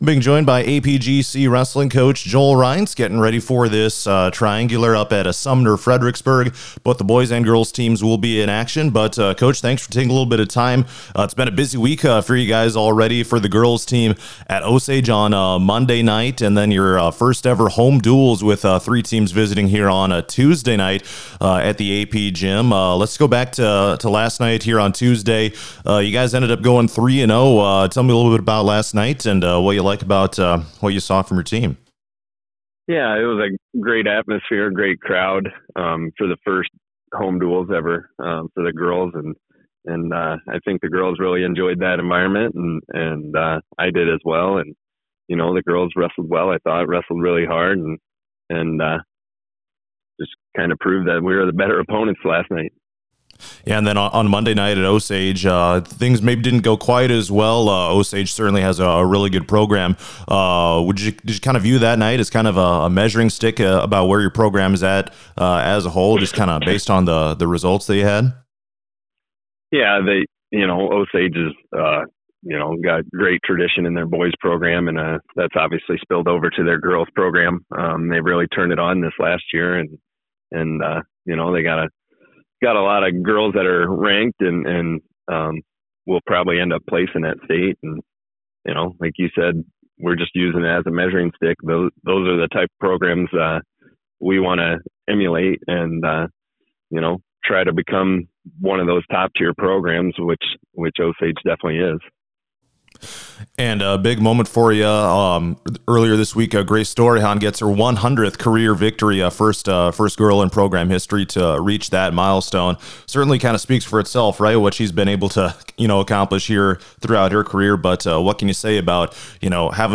preview interview: